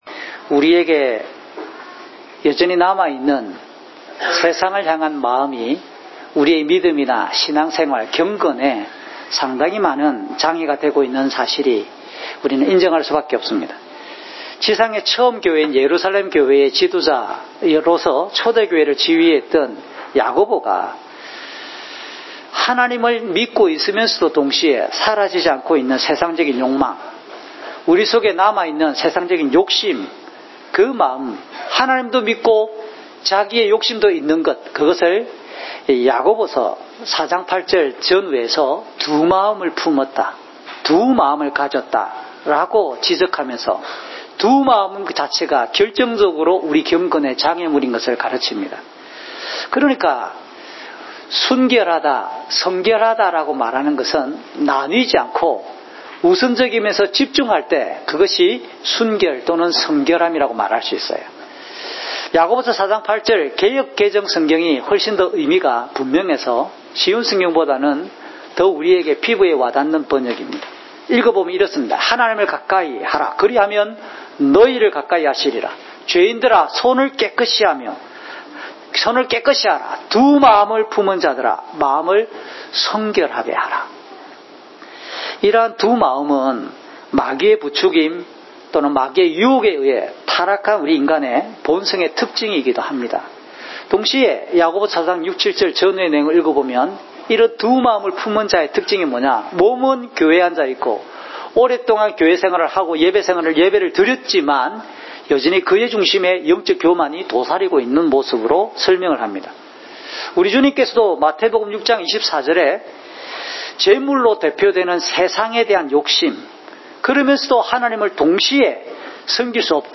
주일설교 - 2019년 5월 26일 “순결한 마음이 참된 경건의 본질입니다!"(삿8:1~35)